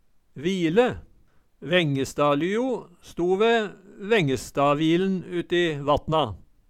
vile - Numedalsmål (en-US)